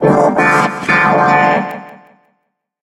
evil_rick_lead_vo_03.ogg